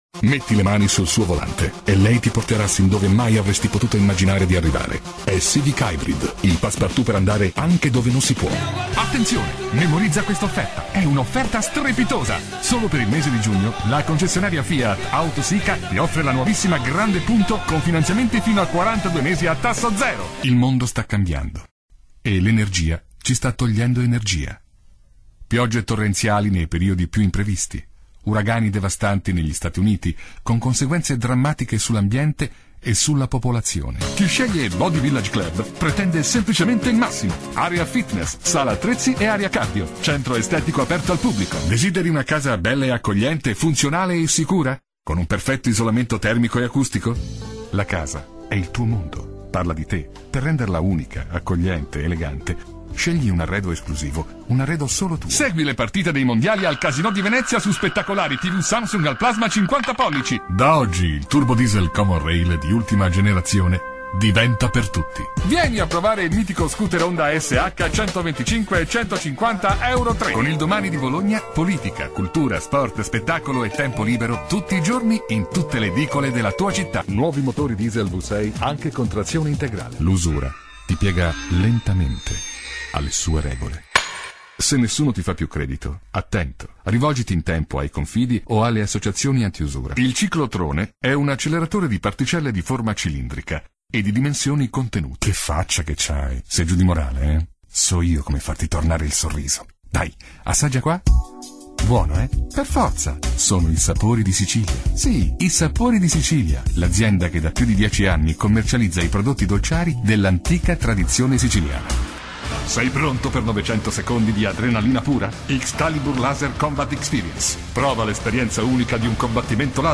Speaker italiano, esperienza trentennale, voce adattabile a qualsiasi estensione vocale, imitatore, caratterista...
Sprechprobe: Industrie (Muttersprache):